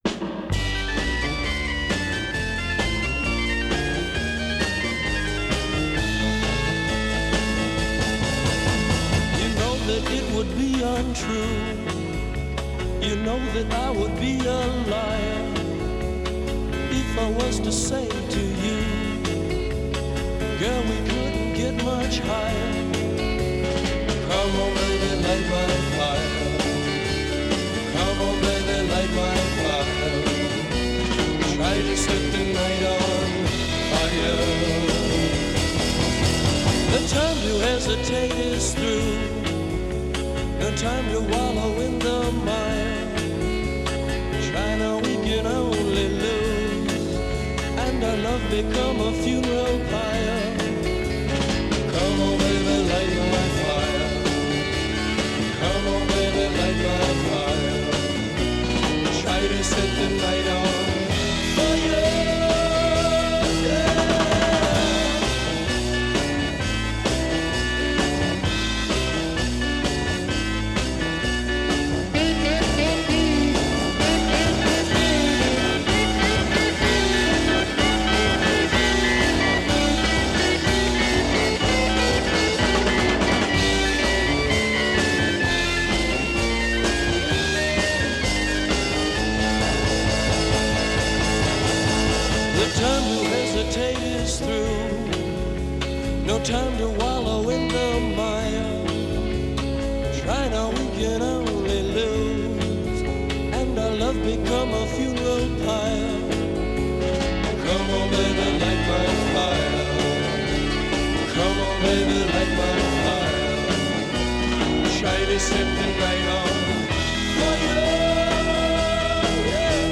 Transferred from high-res 24/192.